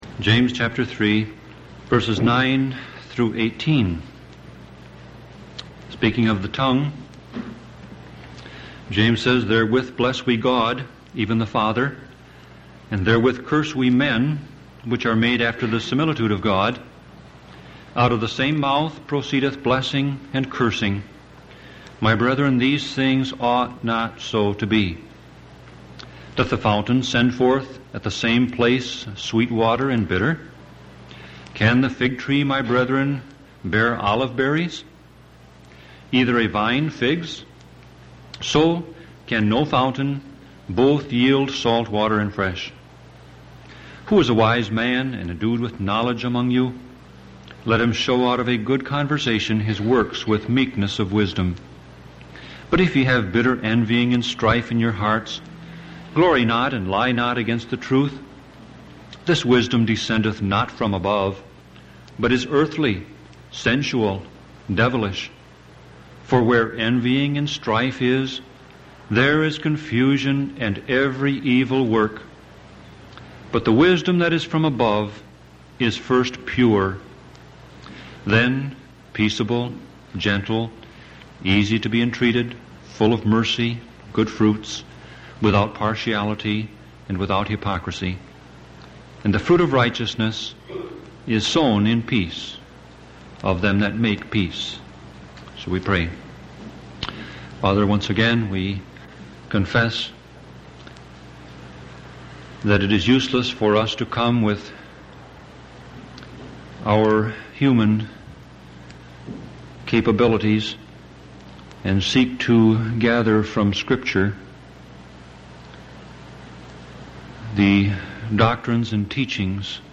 Sermon Audio Passage: James 3:9-18 Service Type